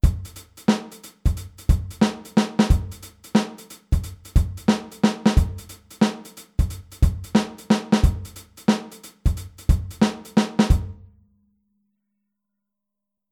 Der Handsatz beim Shufflen